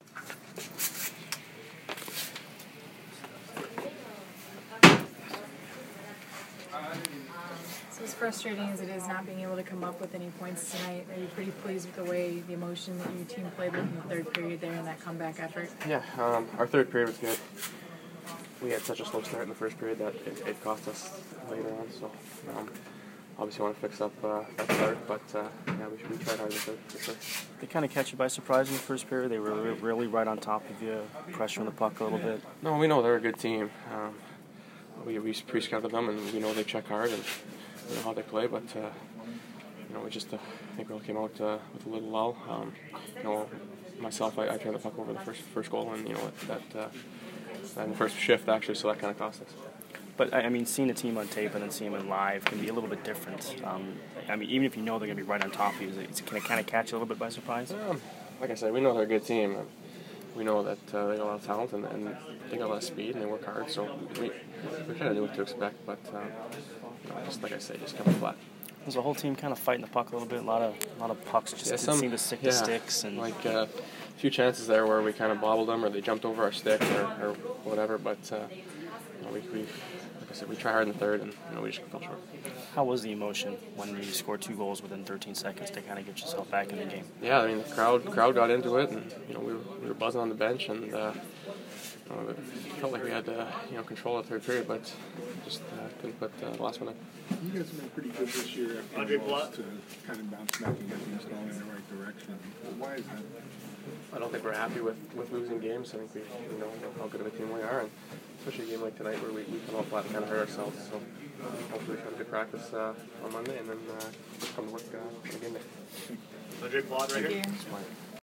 Brayden Point Post-Game 11/18